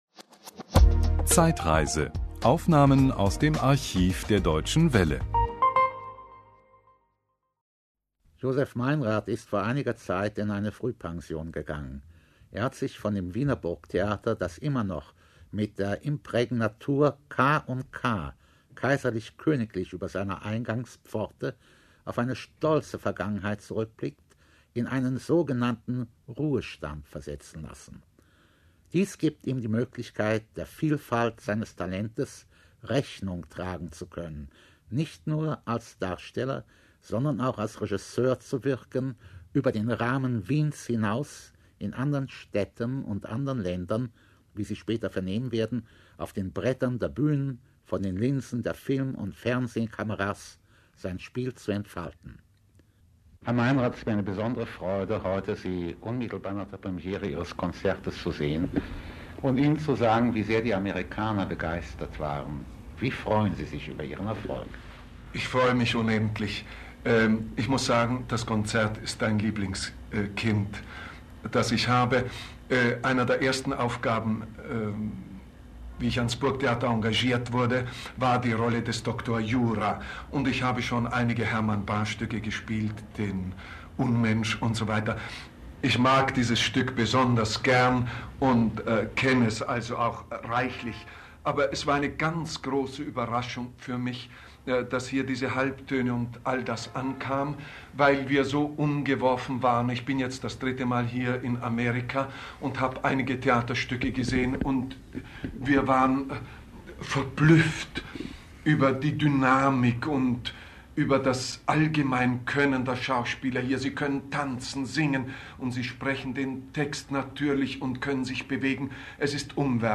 Interview mit Josef Meinrad.